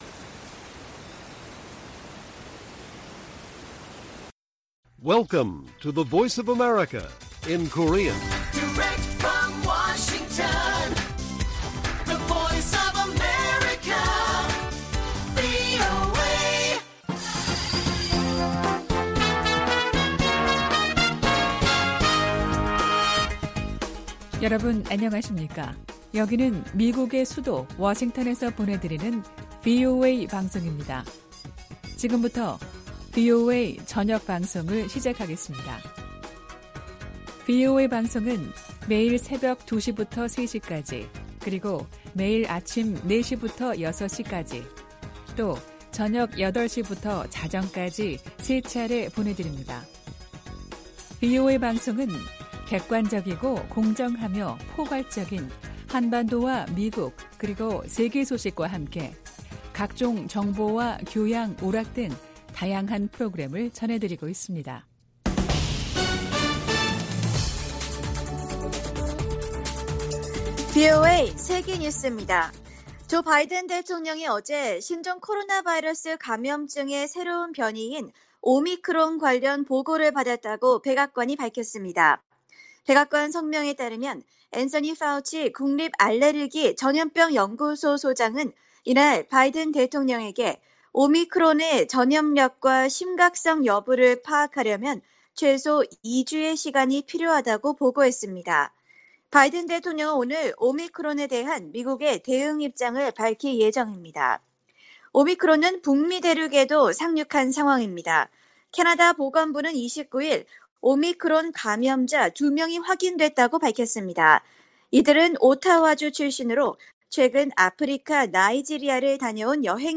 VOA 한국어 간판 뉴스 프로그램 '뉴스 투데이', 2021년 11월 29일 1부 방송입니다. 북한이 신종 코로나바이러스 감염증의 새로운 변이종인 ‘오미크론’의 등장에 방역을 더욱 강화하고 있습니다. 북한의 뇌물 부패 수준이 세계 최악이라고, 국제 기업 위험관리사가 평가했습니다. 일본 정부가 추경예산안에 68억 달러 규모의 방위비를 포함시켰습니다.